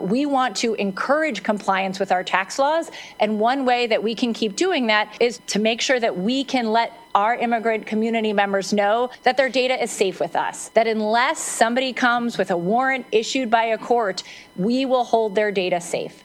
Maryland Comptroller Brooke Lierman testified in Annapolis recently in support of Senate Bill 977, the Maryland Data Privacy Act.  The legislation would require law enforcement and units of State government to deny access to certain databases or facilities for the purpose of enforcing federal immigration law.